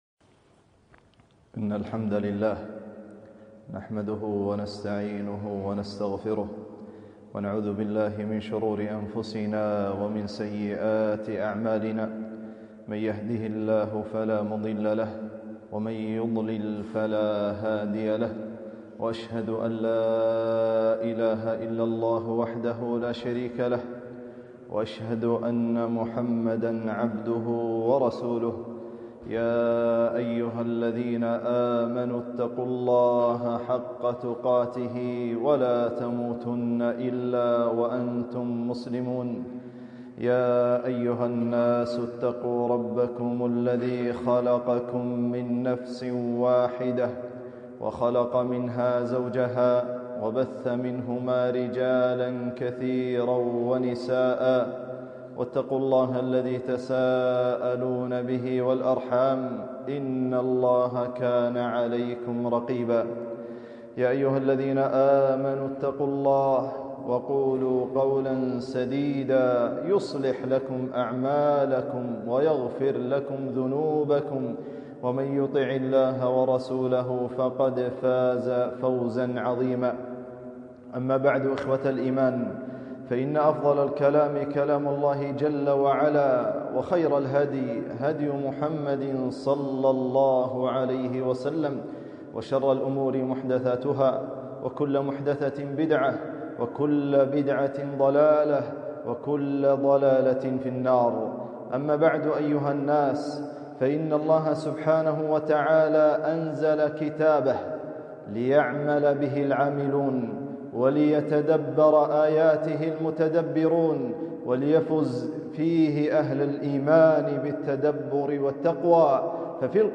خطبة - تفسير آية فيها صلاح الدنيا والآخرة